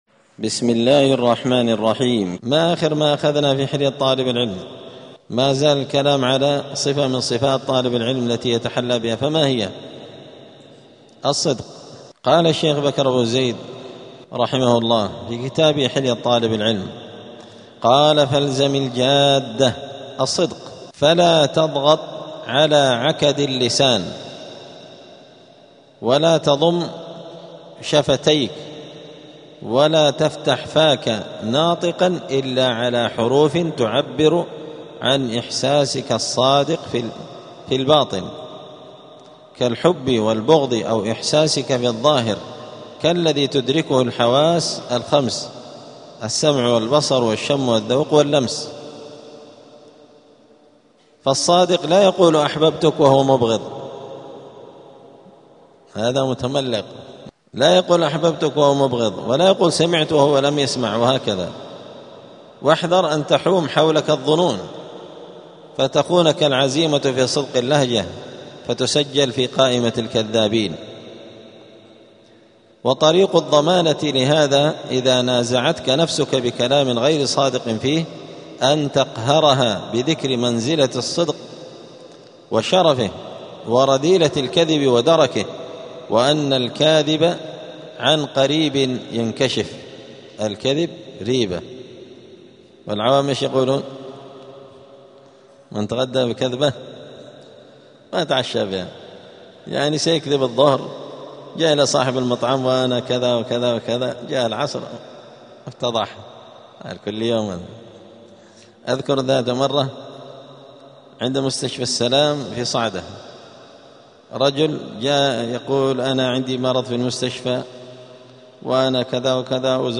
الجمعة 4 شعبان 1447 هــــ | الدروس، حلية طالب العلم، دروس الآداب | شارك بتعليقك | 6 المشاهدات